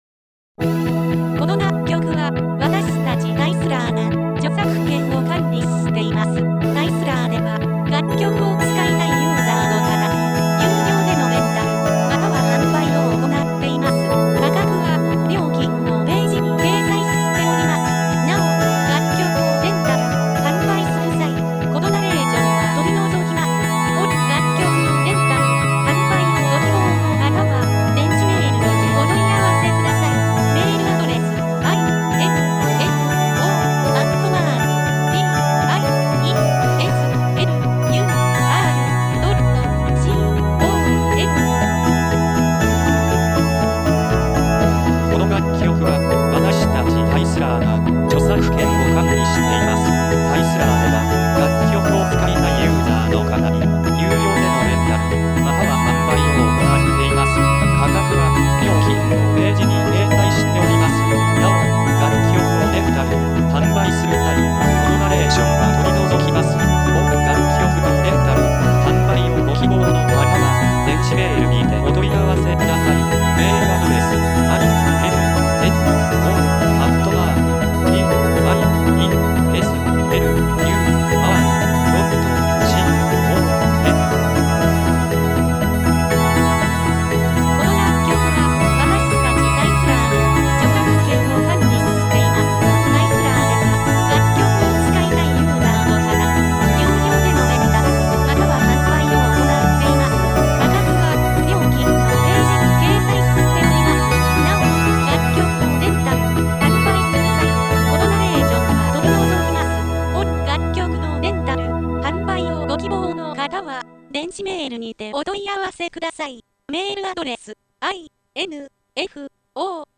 ■インスト曲のダウンロード販売・レンタルのページ
●メジャーキー・スローテンポ系
●メジャーキー・ミドルテンポ系